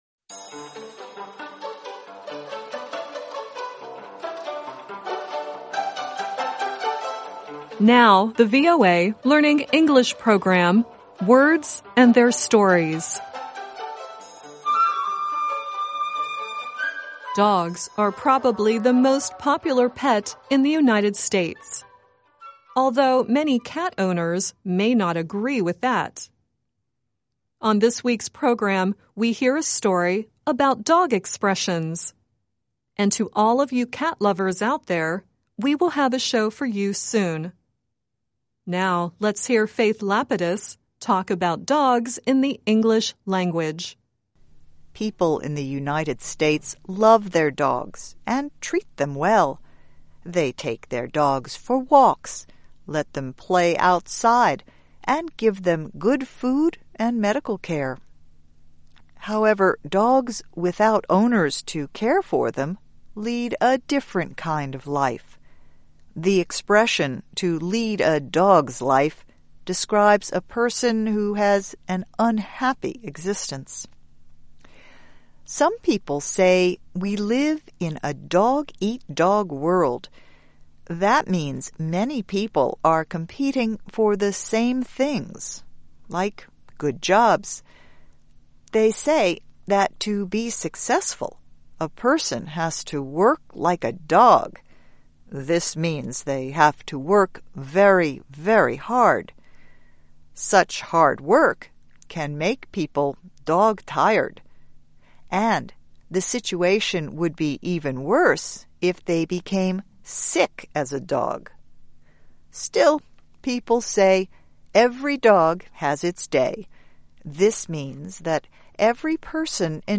And the song at the end is Elvis Presley singing “Hound Dog.”